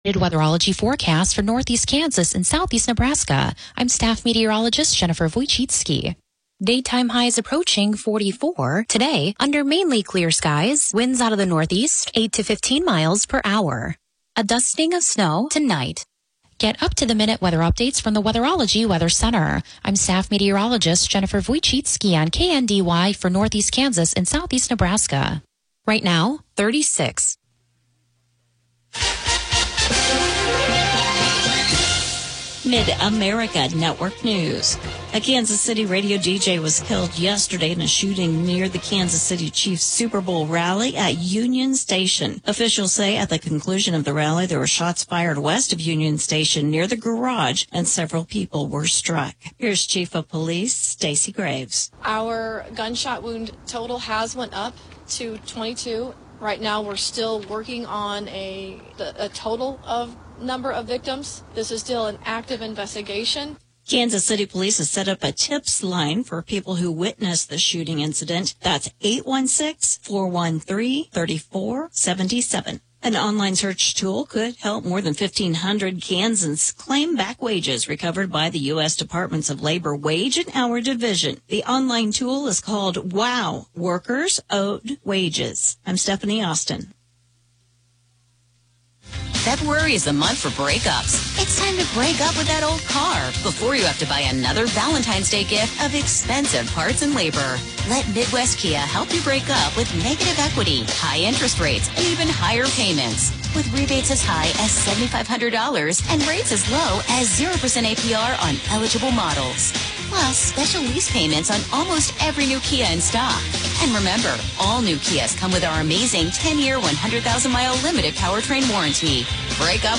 The KNDY Midday News podcast gives you local, regional, and state news as well as relevant information for your farm or home as well. Broadcasts are archived daily as originally broadcast on Classic Country 1570 AM, 94.1 FM KNDY.